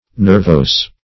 \ner*vose"\ (n[~e]r*v[=o]s"), a.